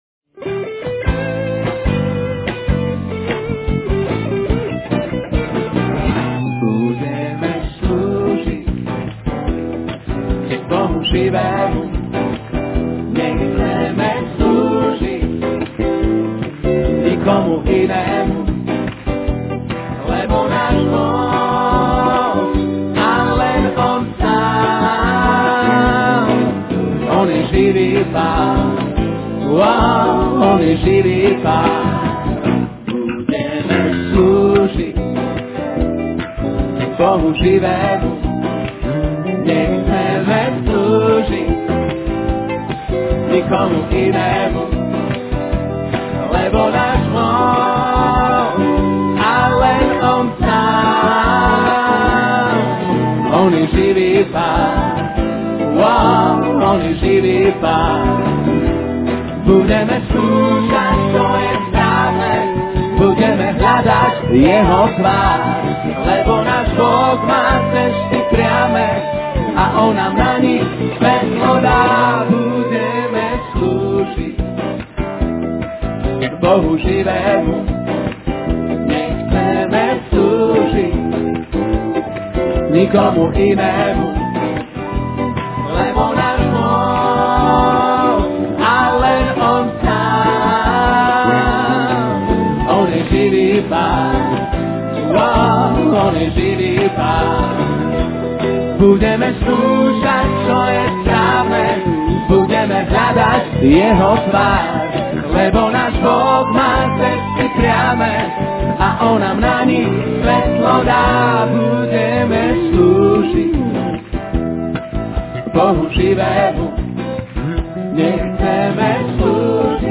The New Covenant - Slovakia Conference 2003
In this sermon, the speaker encourages the congregation to gather in small groups and pray for one another. He emphasizes the importance of unity in the body of Christ and the need to love one another.